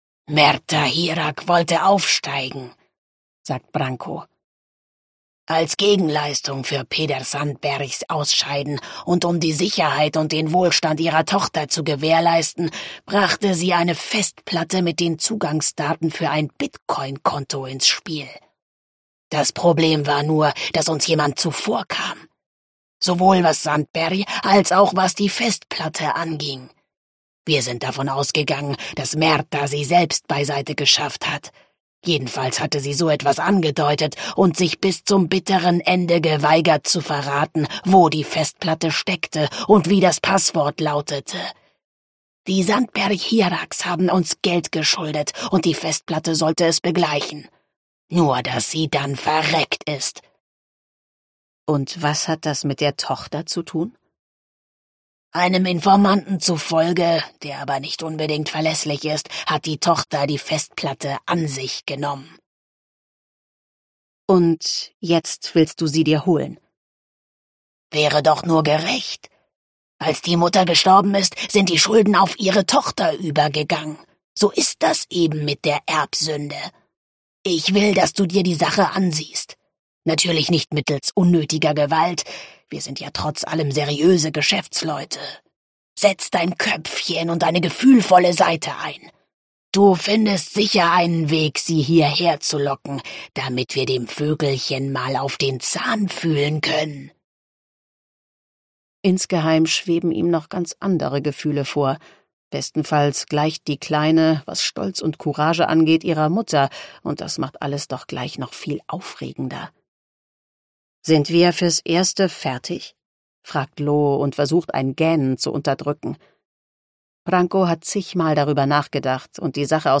Gekürzte Lesung Random House Audio